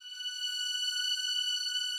Added more instrument wavs
strings_078.wav